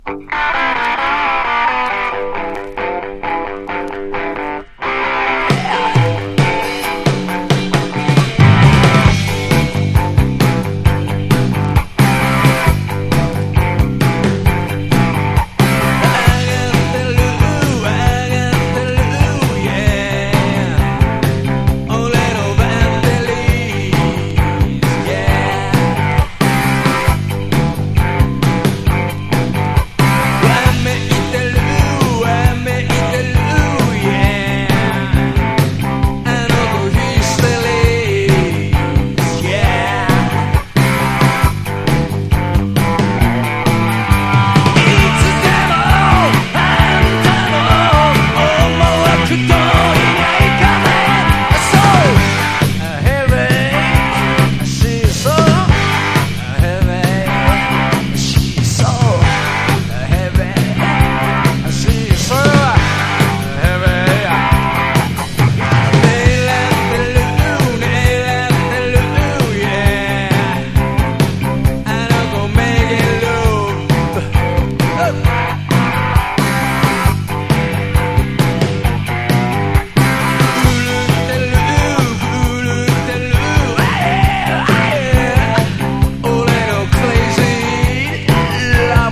伝説のロックバンド